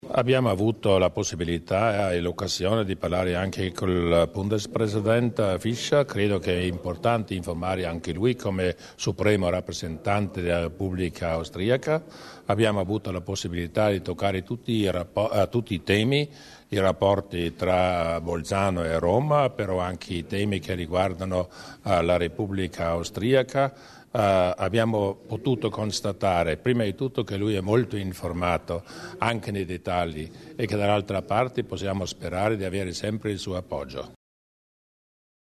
Il Presidente Durnwalder sull'incontro con il Presidente Fischer